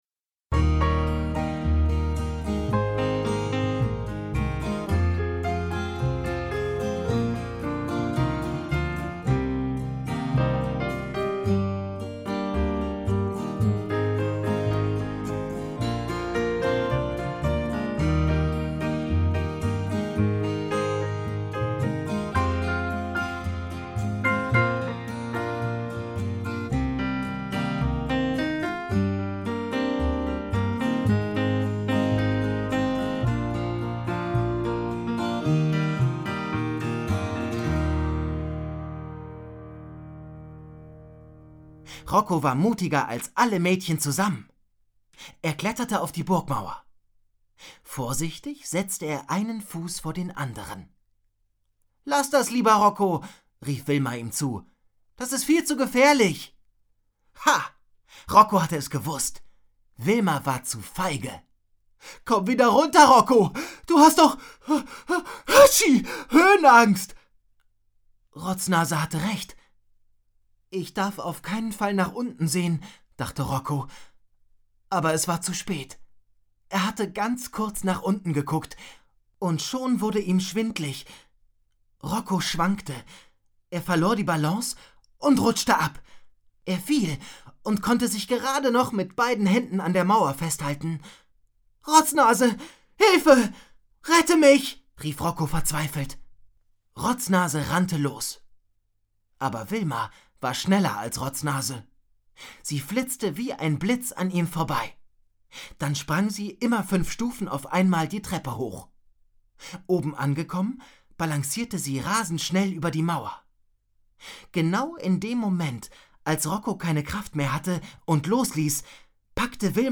Hörbuch, 1 CD, ca. 55 Minuten